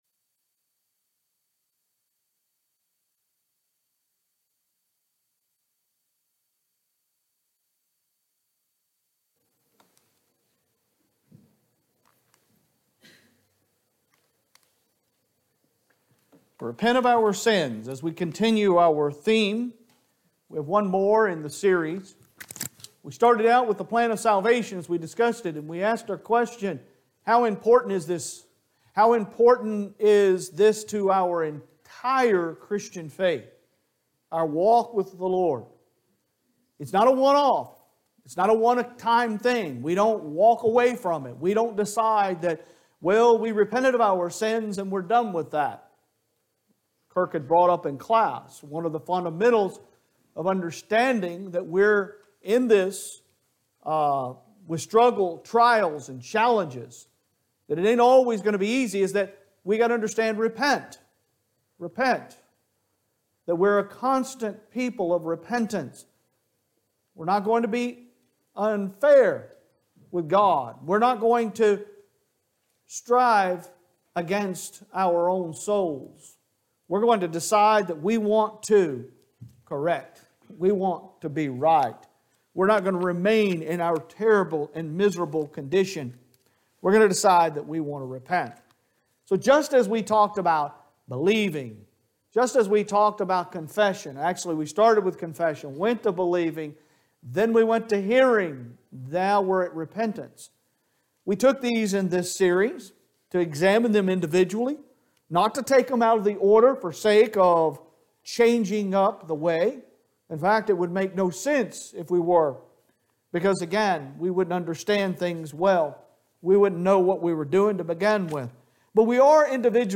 Sunday AM Sermon – Repentance